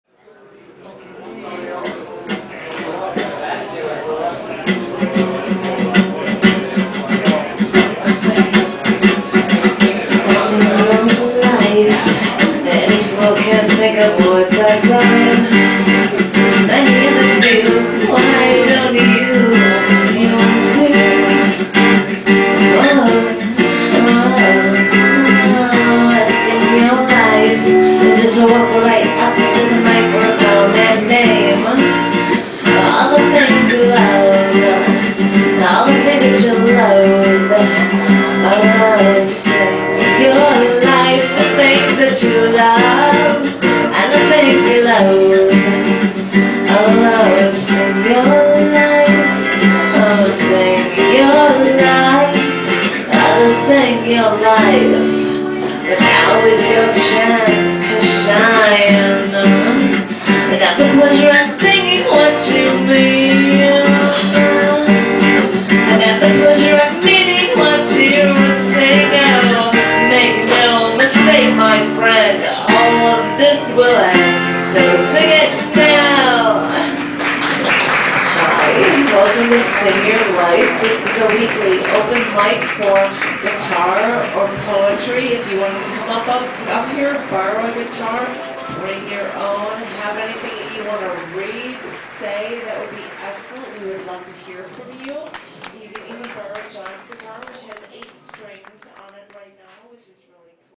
Poetry was mixed with music during this set.